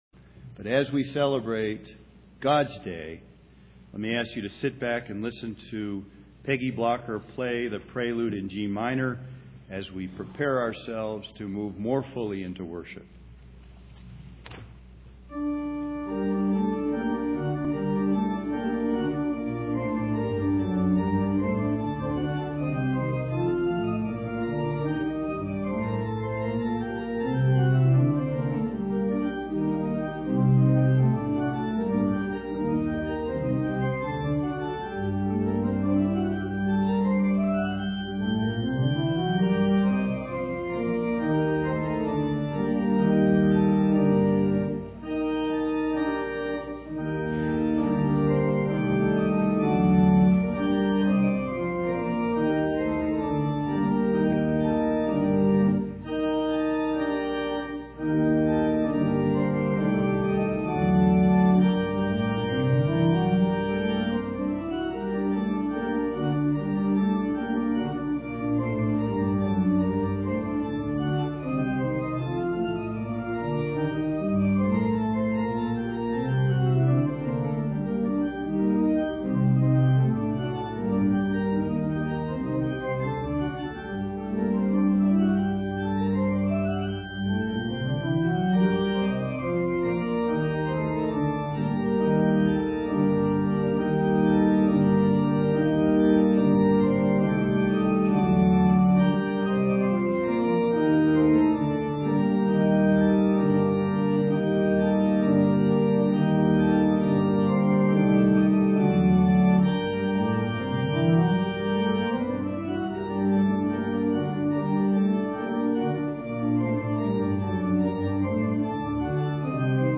2007 World Communion Sunday Service
Prelude                                                "